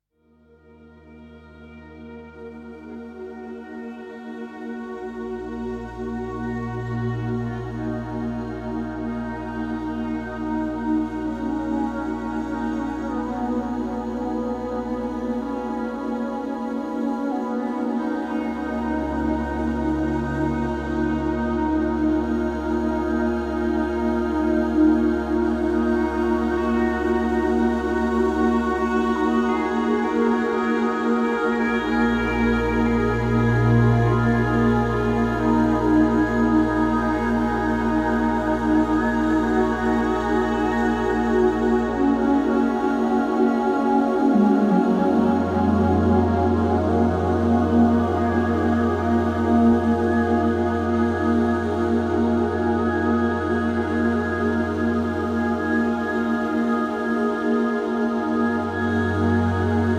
German electronic music